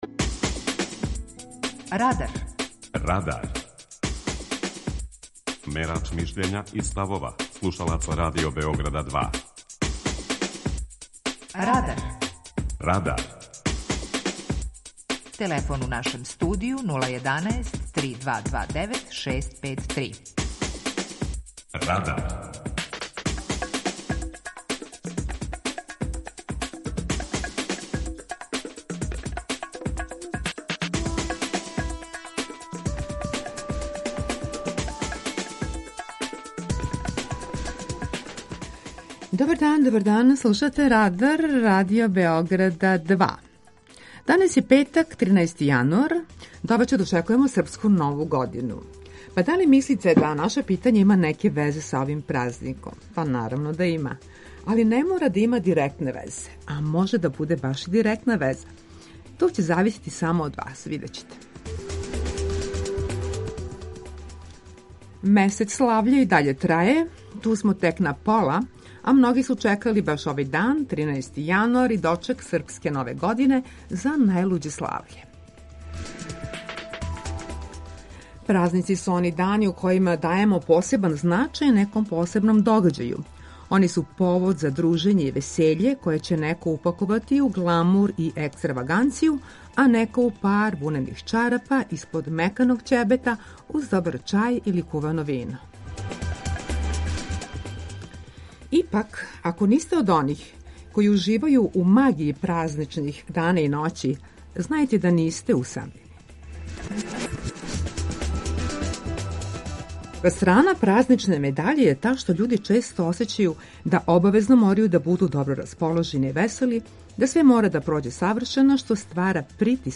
Питање Радара је: Који празник вам је најдражи? преузми : 19.04 MB Радар Autor: Група аутора У емисији „Радар", гости и слушаоци разговарају о актуелним темама из друштвеног и културног живота.